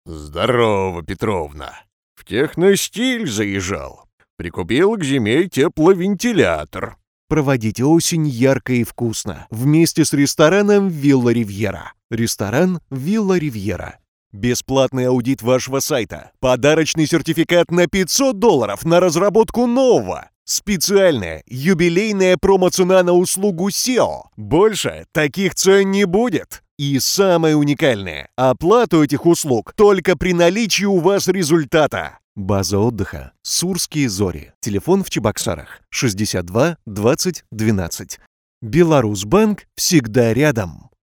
Тракт: дикторская кабина, микрофон Neumann TLM 103, преамп Drawmer MX60, карта Presonus 22VSL
Демо-запись №1 Скачать